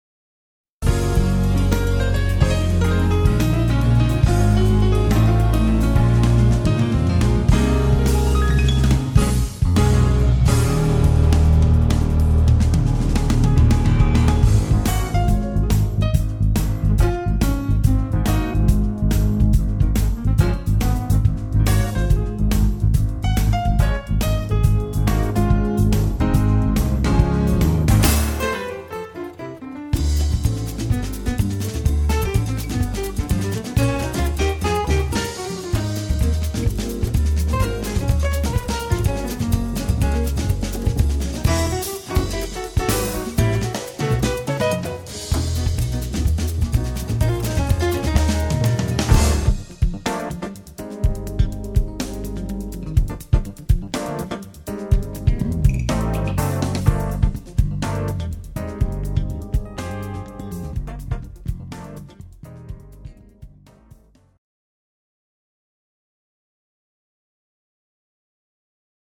I did a couple records and toured with this jazz/funk artist